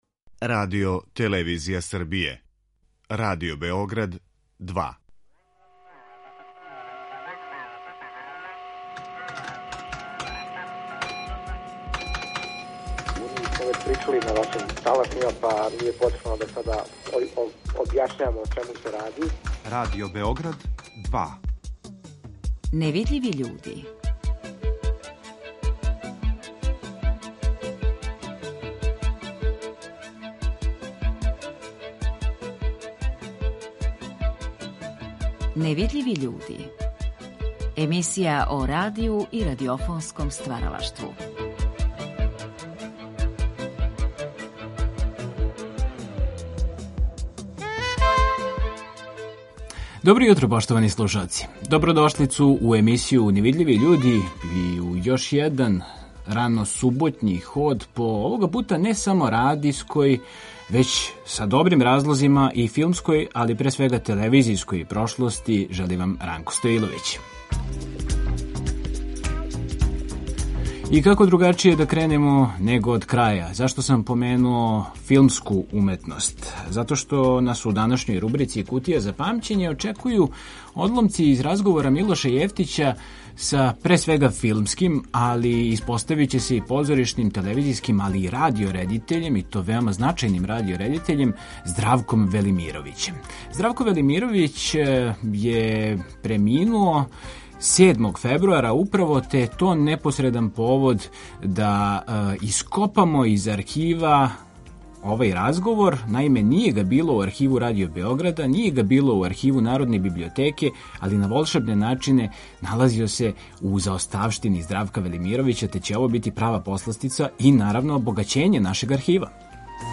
У данашњој рубрици „Кутија за памћење" емитујемо одломке из разговора Милоша Јевтића са филмским, телевизијским, радио и позоришним редитељем и сценаристом Здравком Велимировићем. Овај разговор вођен је за циклус емисија „Гост Другог програма" 1991. године.